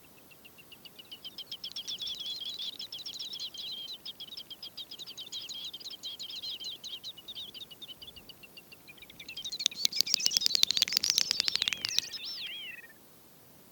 Dobbeltbekkasin (Gallinago media).
Sang under leik